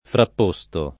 vai all'elenco alfabetico delle voci ingrandisci il carattere 100% rimpicciolisci il carattere stampa invia tramite posta elettronica codividi su Facebook frapposto [ frapp 1S to ] part. pass. di frapporre — cfr. posto